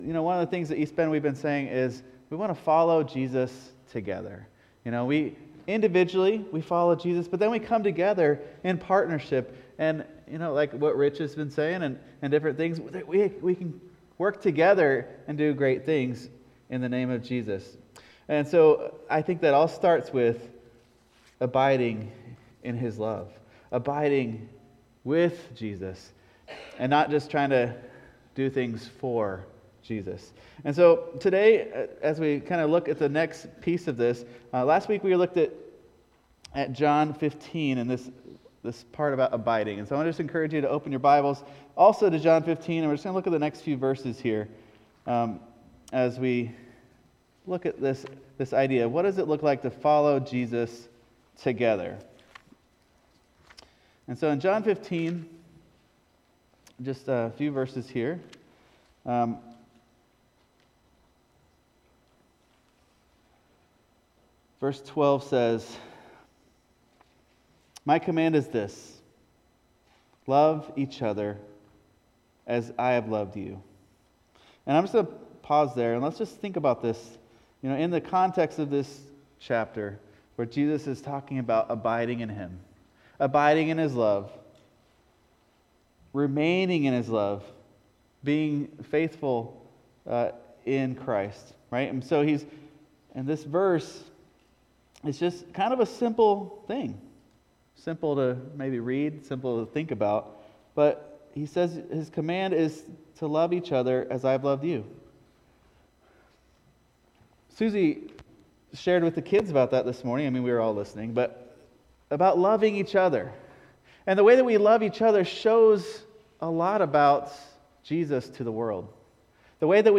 … continue reading 252 ตอน # Religion # Sunday Service # East Bend Mennonite Church # Christianity